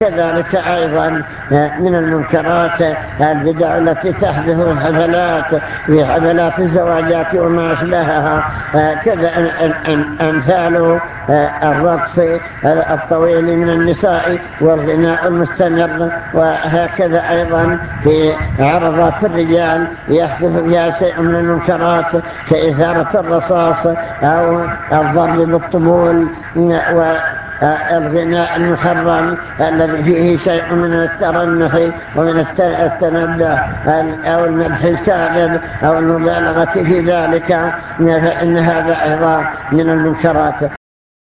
المكتبة الصوتية  تسجيلات - محاضرات ودروس  محاضرة بعنوان من يرد الله به خيرا يفقهه في الدين التحذير من بعض المنكرات